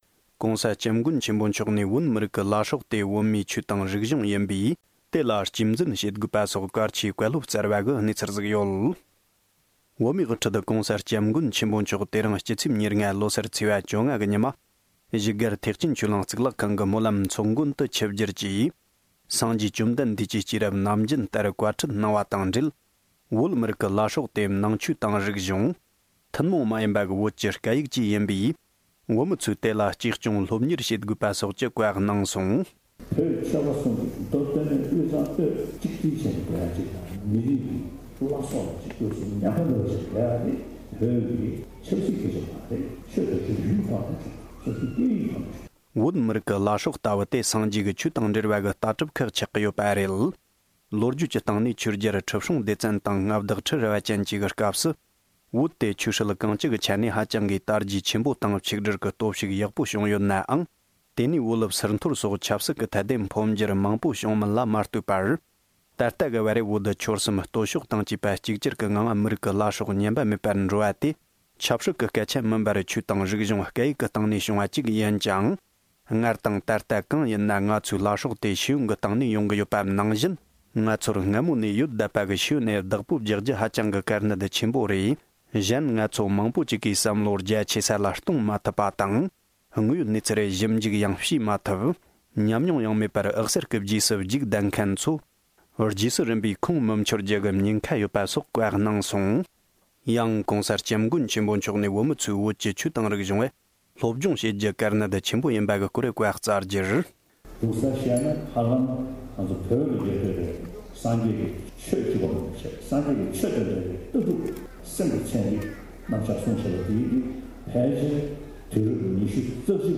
སྒྲ་ལྡན་གསར་འགྱུར།